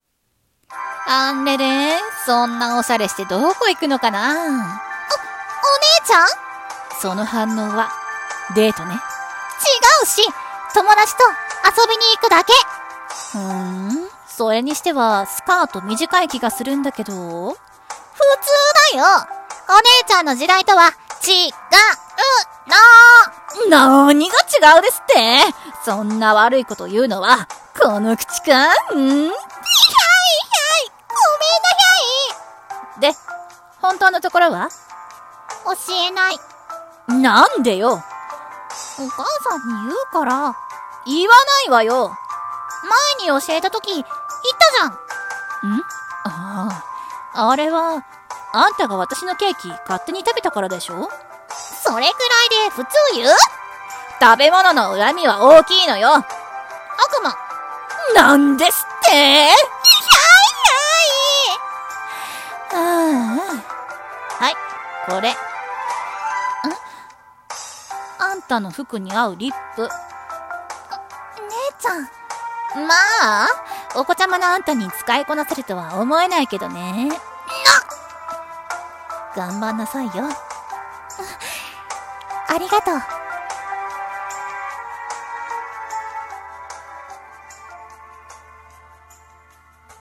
声劇台本 『姉妹～妹side』 二人用